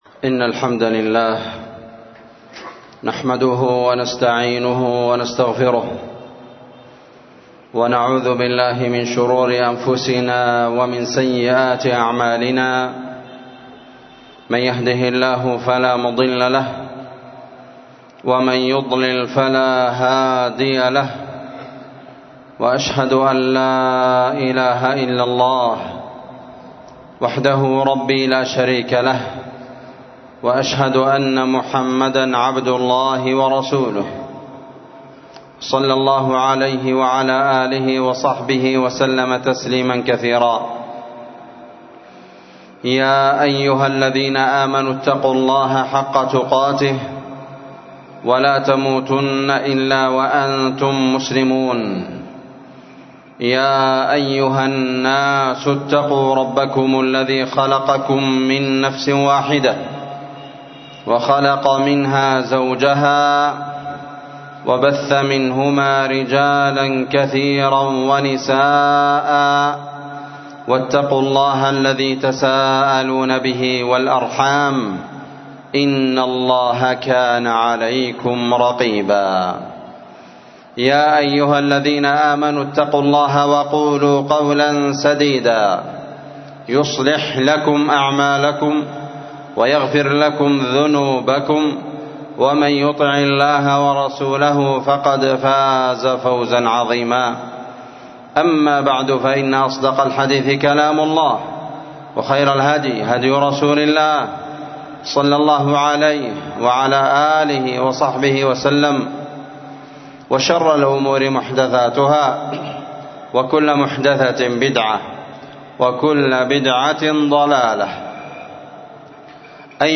خطبة جمعة بعنوان *القول الحق في بيان جريمة قتل النفس المعصومة بغير حق*